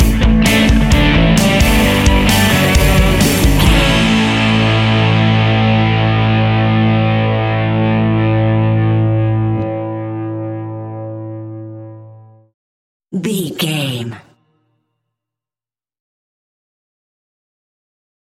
Ionian/Major
energetic
driving
heavy
aggressive
electric guitar
bass guitar
drums
hard rock
heavy drums
distorted guitars
hammond organ